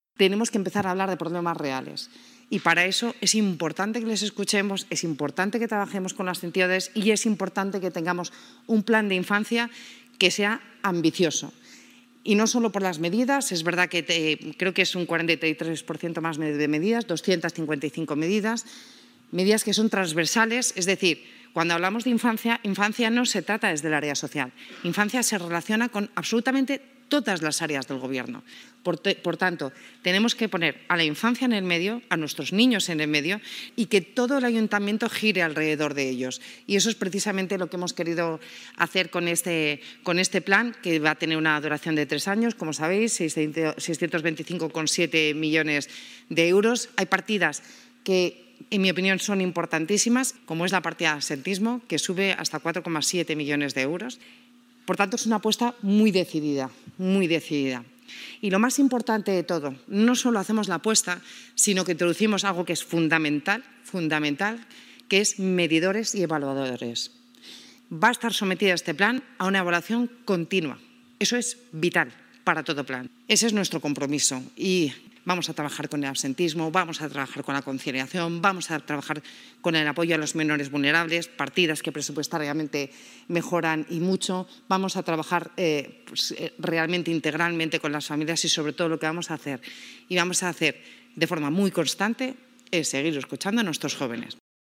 Begoña Villacís, vicealcaldesa, presenta el Plan de Infancia y Adolescencia del Ayuntamiento de Madrid Pepe Aniorte, delegado del Área de Familias, Igualdad y Bienestar Social, explica las líneas estratégicas del Plan de Infancia y Adolescencia